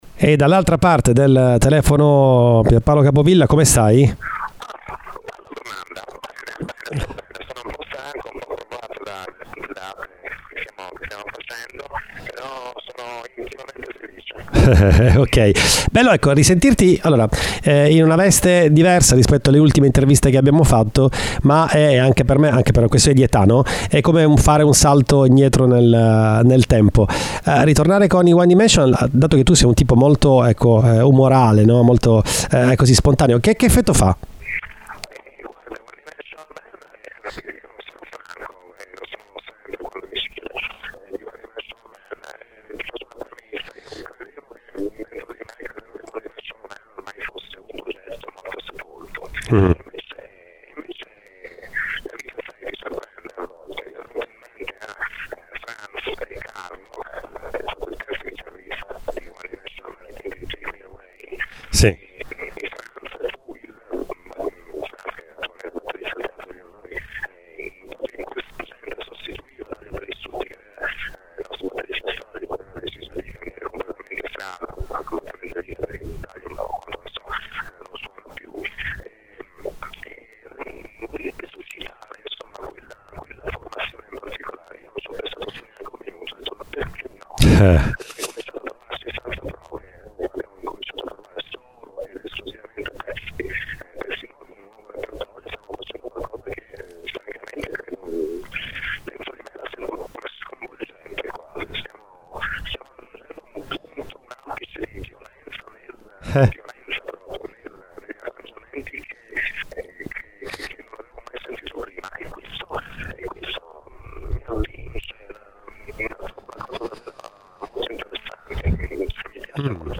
Ecco l’intervista fatta oggi a PIERPAOLO CAPOVILLA: